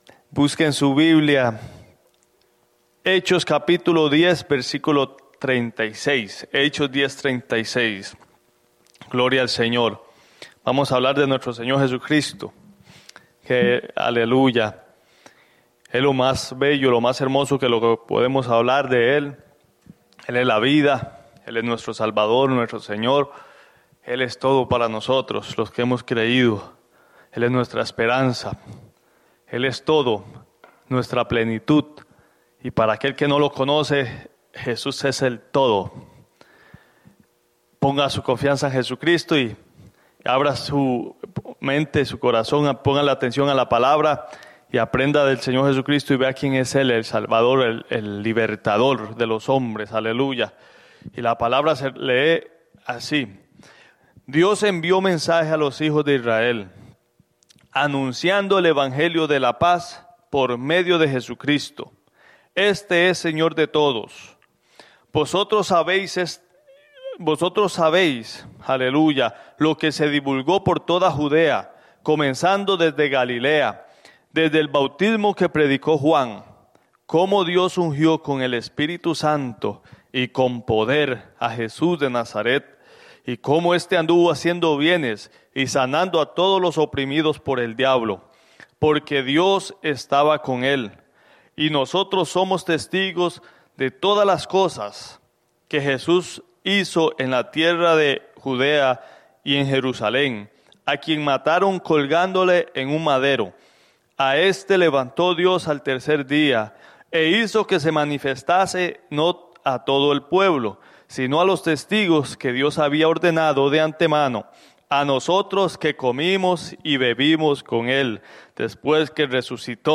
Grabado 02/18/2021 en Souderton, PA